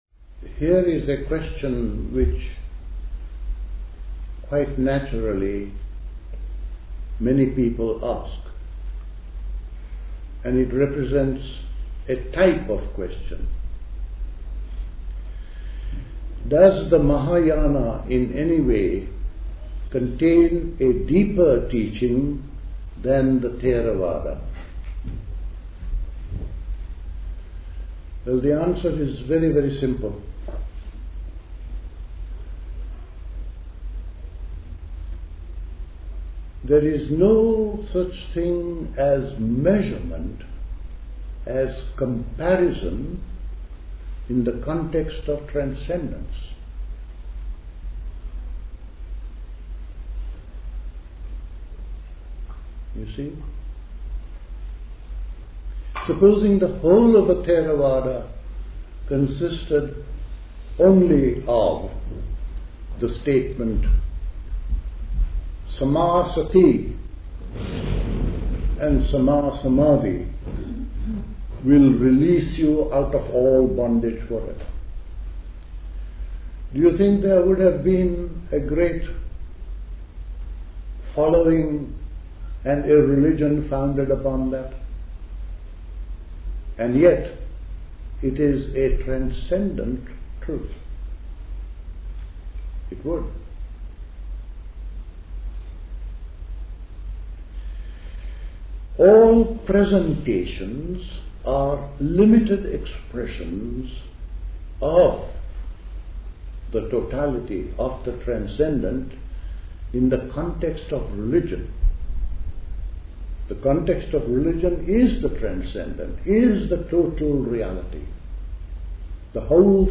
A talk
at High Leigh Conference Centre, Hoddesdon, Hertfordshire